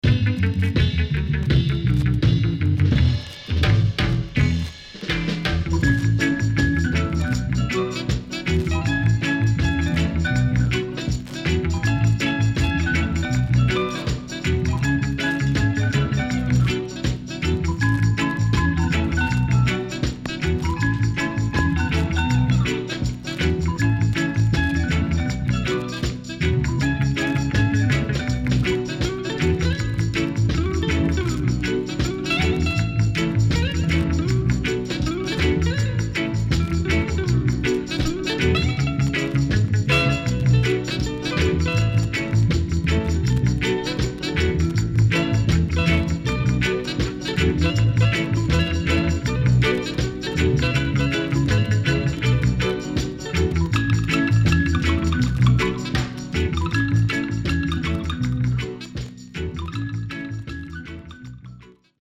CONDITION SIDE A:VG(OK)
SIDE A:プレス起因により少しチリプチノイズ入りますが良好です。